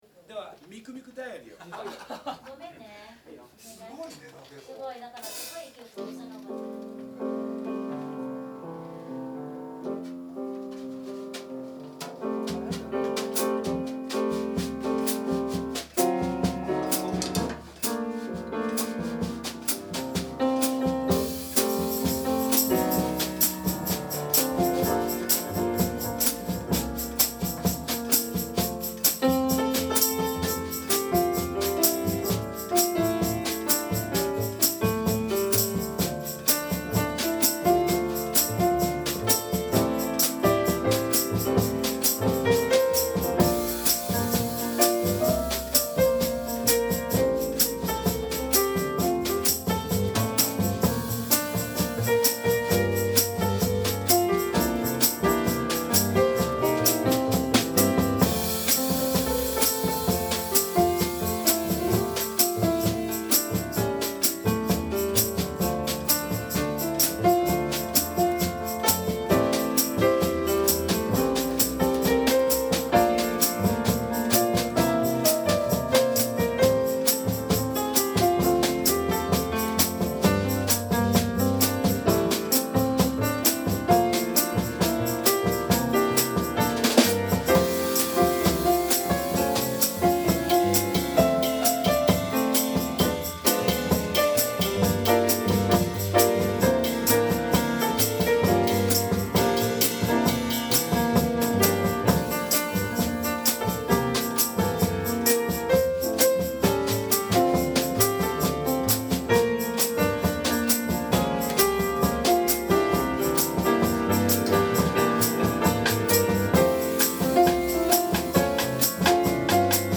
という訳で、師匠が代わりに弾いてくれました！
Piano
Bass
Drums
録音：Hi-MDウォークマン MZ-RH1、マイクロフォン ECM-MS957、Hi-SP録音
diary_bossa.mp3